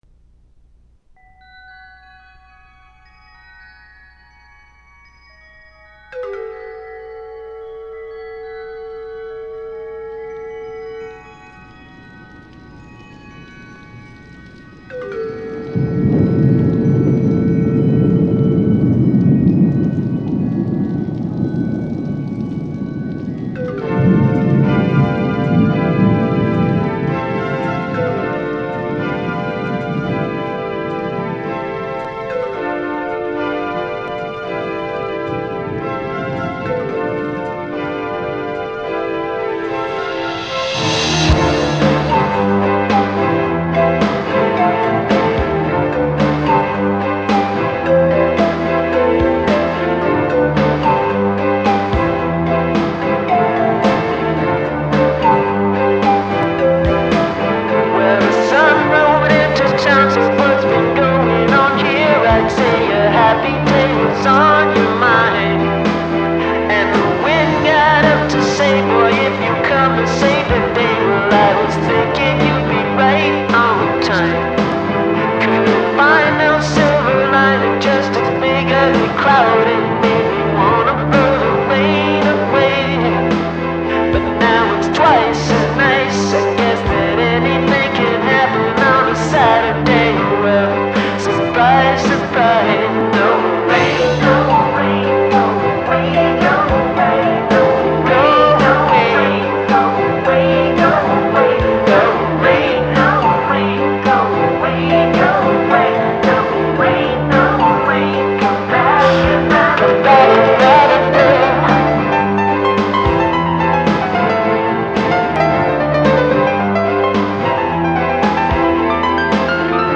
half-baked rock/noise/folk/pop